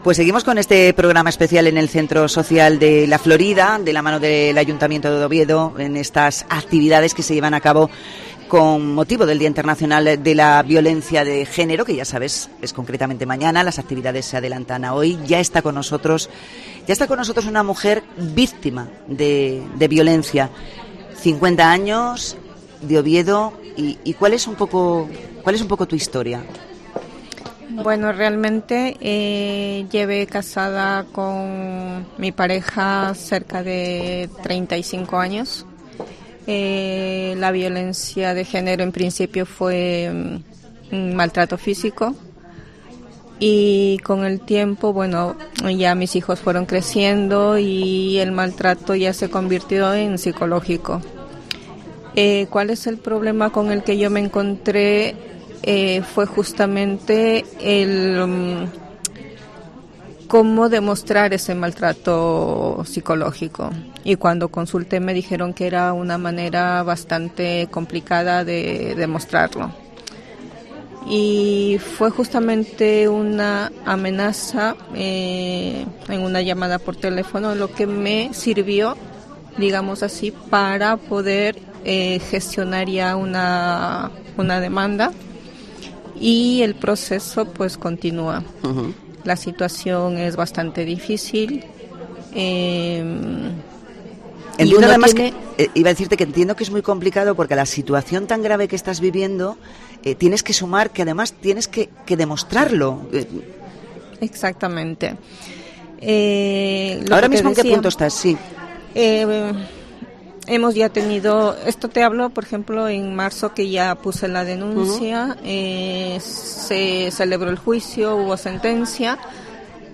Hablamos con una mujer víctima de violencia de género